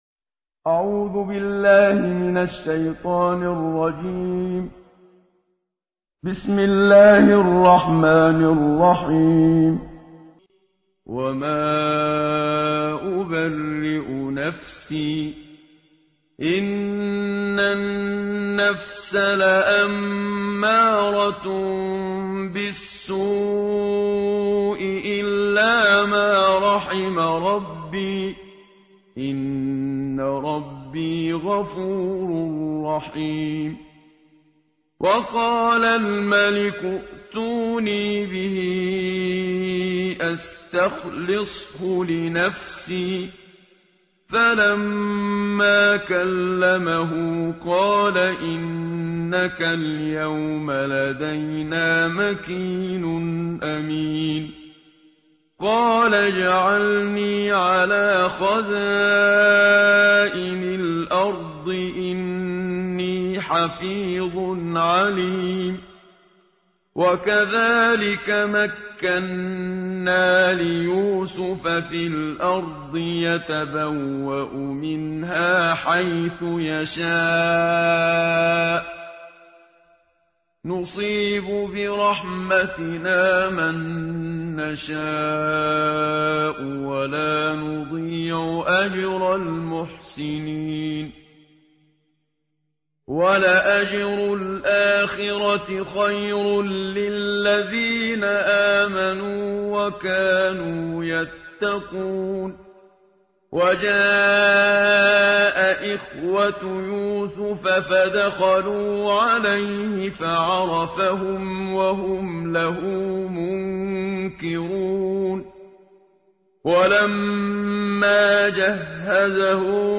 ترتیل جزء سیزدهم قرآن با صدای استاد منشاوی
تهران- الکوثر: در سیزدهمین روز ماه مبارک رمضان، تلاوت جزء سیزدهم قرآن کریم را با صدای قاری مشهور مصری استاد محمد صدیق منشاوی، با هم می شنویم.